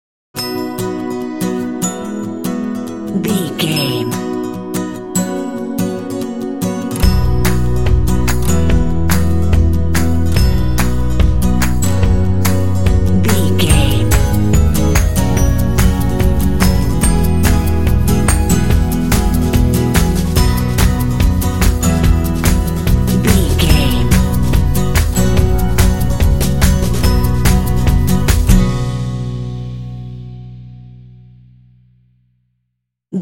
Uplifting
Ionian/Major
Fast
happy
energetic
acoustic guitar
bass guitar
drums
percussion
alternative rock
pop
indie